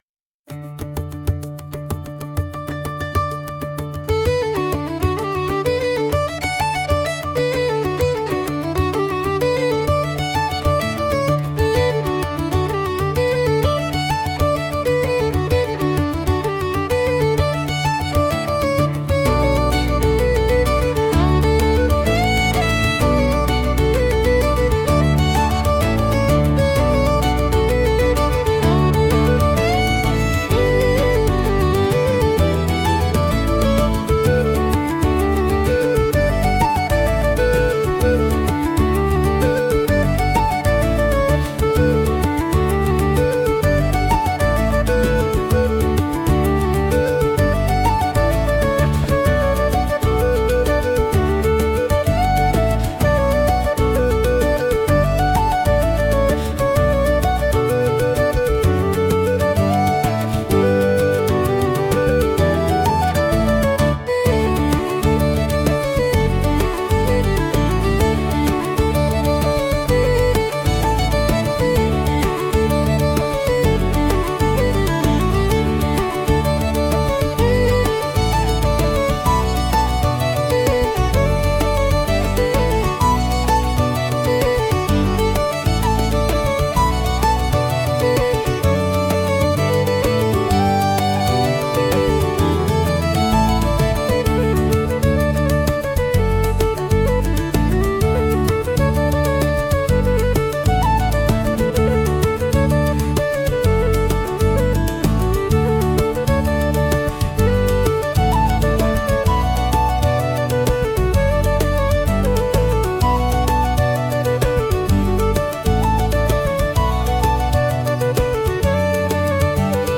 calm instrumental playlist